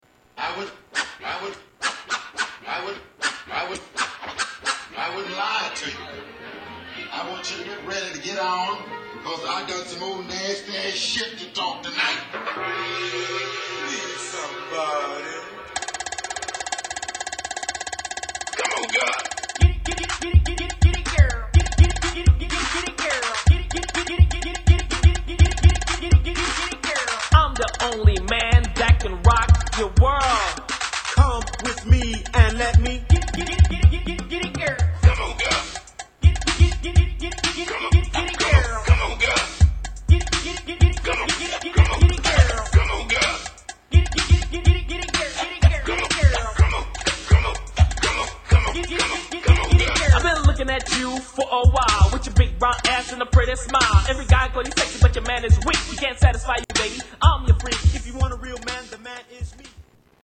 Rap
Hip Hop